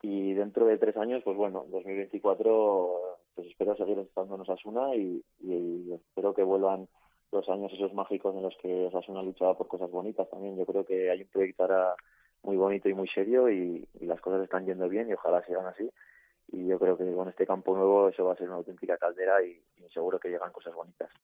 Entrevista en COPE Navarra a Jon Moncayola, jugador de Osasuna